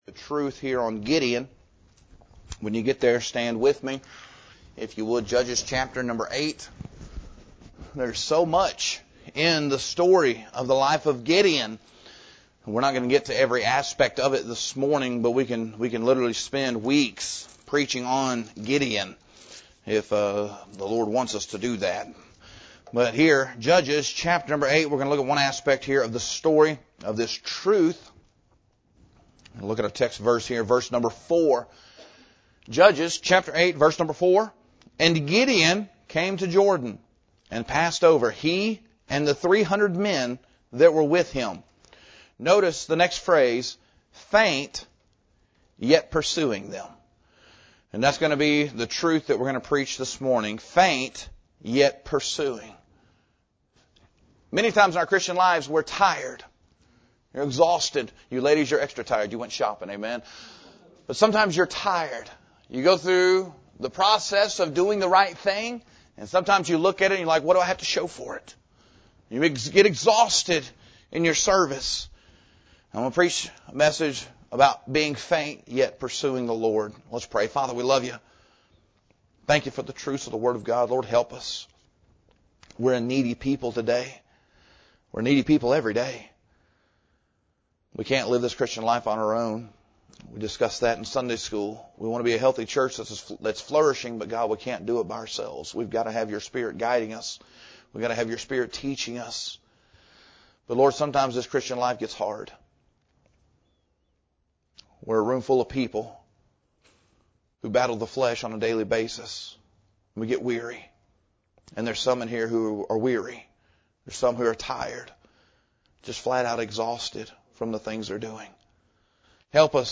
Title: “Faint, Yet Pursuing” — This sermon examines a short, powerful phrase in Judges 8:4 that describes a familiar condition of many Christians: worn out in the flesh yet still pressing on in faith and obedience.